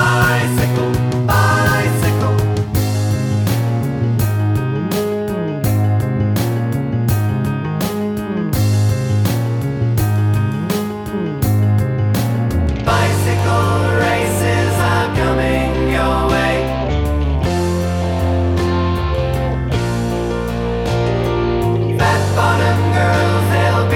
One Semitone Down Rock 2:59 Buy £1.50